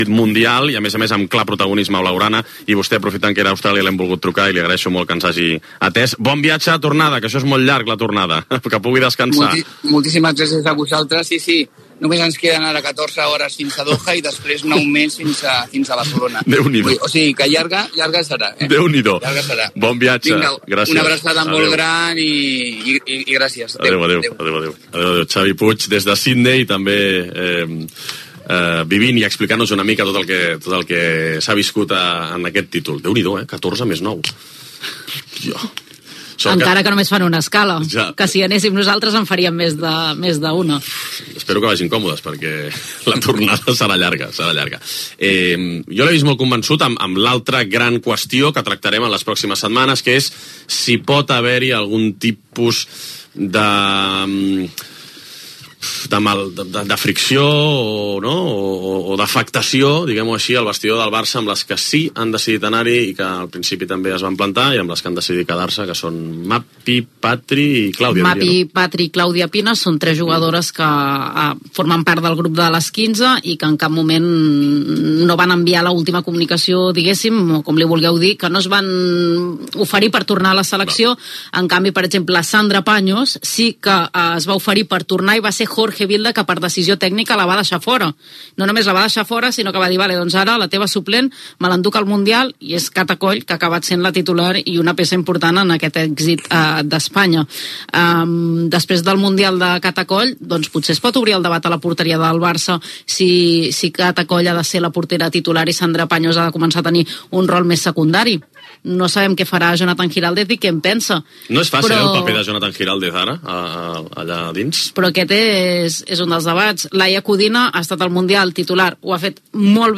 Lliga de futbol masculí. Connexió amb Sidney.
Gènere radiofònic Esportiu